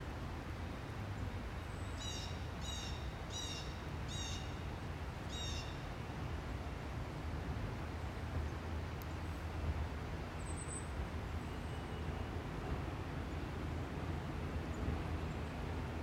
Compare those with the call of the Blue Jay, which is sometimes described as brash or coarse.
Audio File of Blue Jay calling, CVNP, April 6, 2021. Recording by Conservancy staff.
Blue-jay.m4a